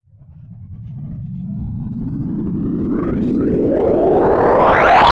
GURGLY.wav